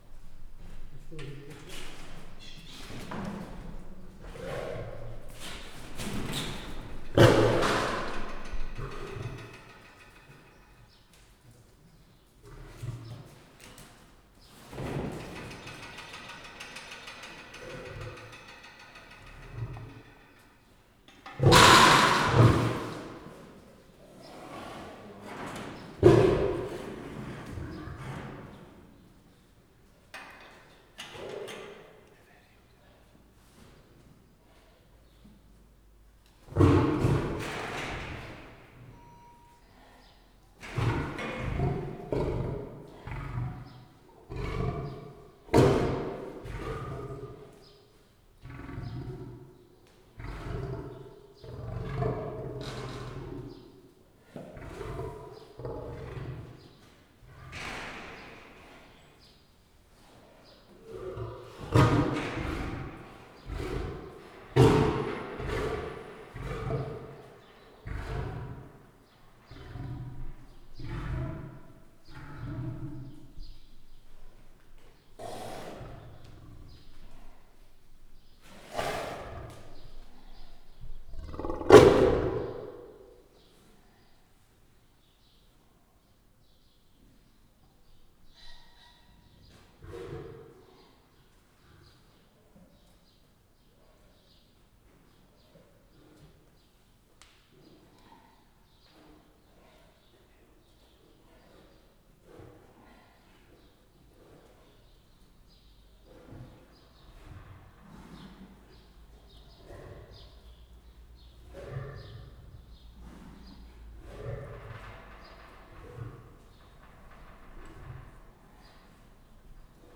kecskemetizoo2016_professzionalis/oroszlan